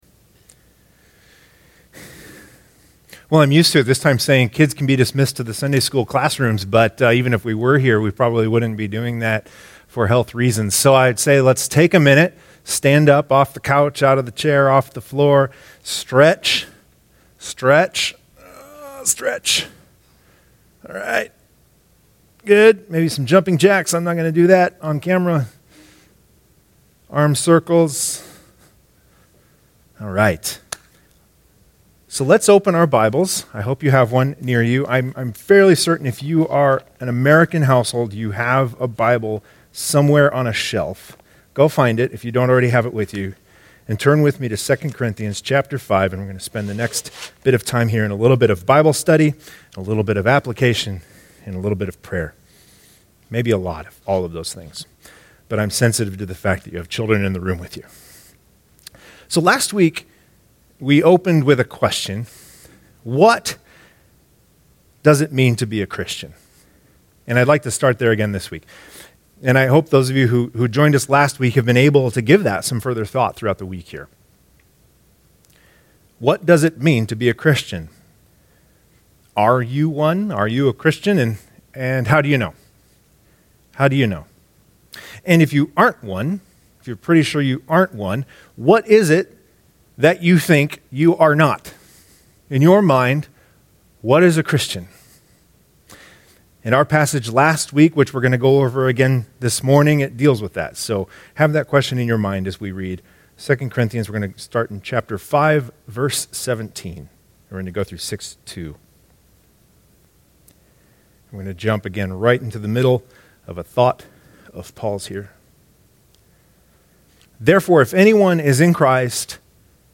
Download today’s sermon notes